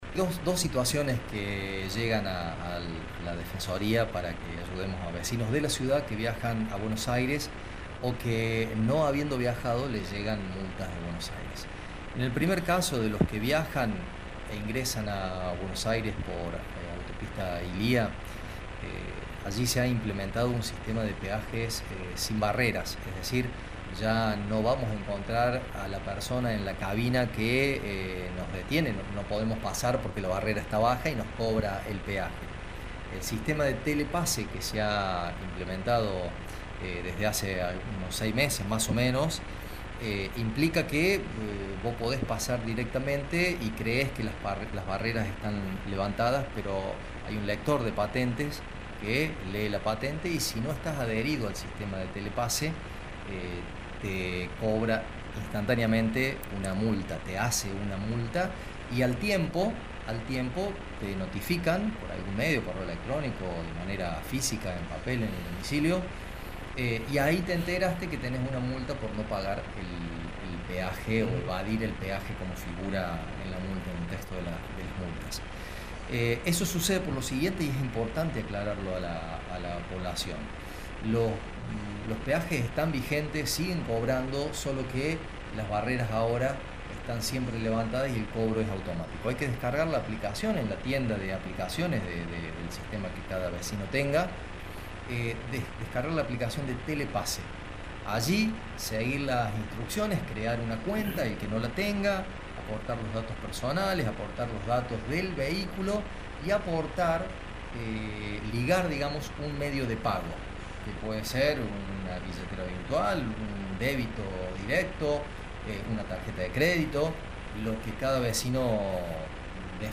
El titular de la Auditoría General, Rafael Sachetto, en diálogo con Radio Show destacó una situación frecuente por la que los vecinos villamarienses recurren a la Defensoría: las multas.